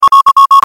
warning_immediate.wav